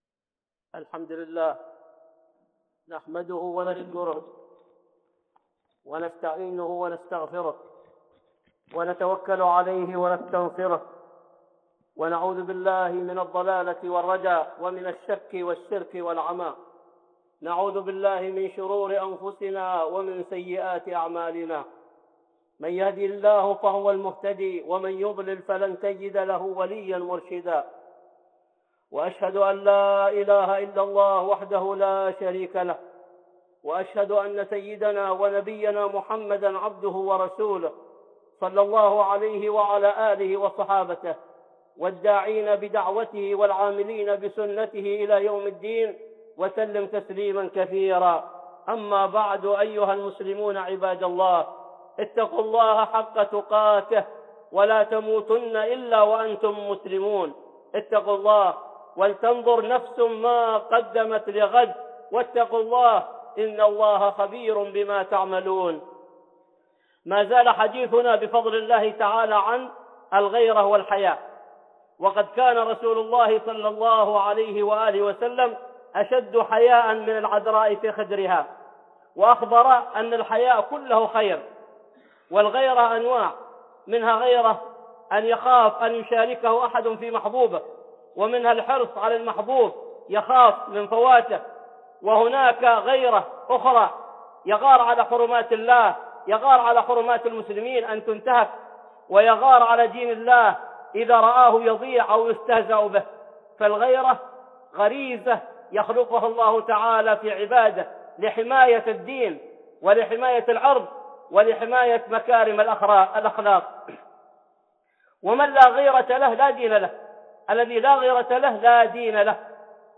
(خطبة جمعة) الغيرة والحياء 2